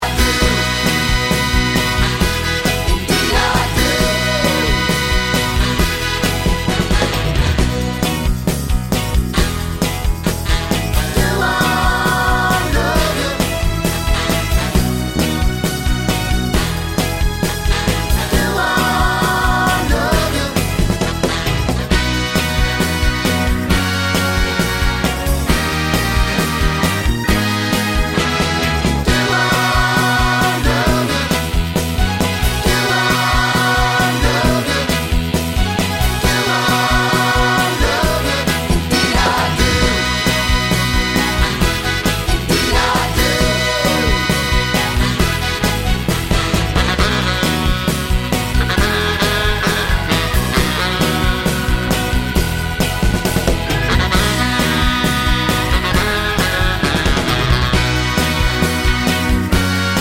Rock 2:24 Buy £1.50